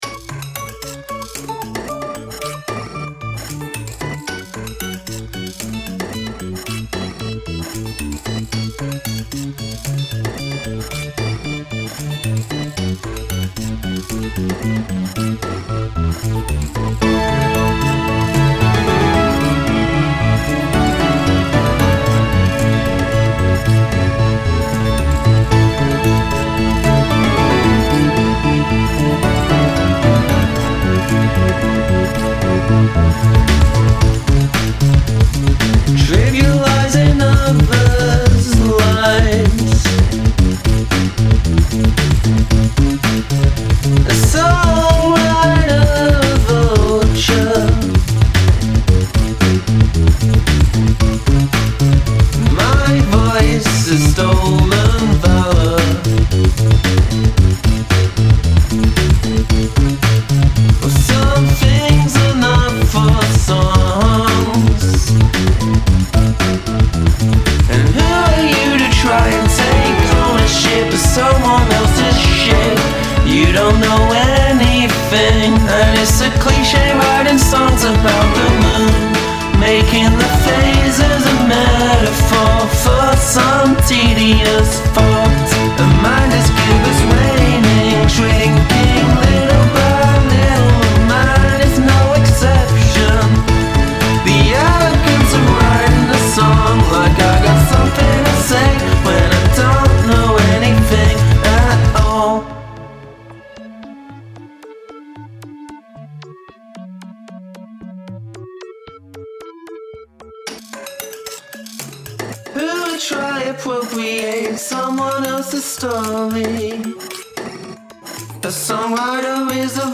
The noisy percussion that drives the stripped down verse is really effective, bet you wish you'd saved it for the sample round though, haha.
I like the found percussion sounds.
Vocals are pitchy.
Yes, you've gone full chiptune!